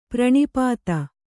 ♪ praṇipāta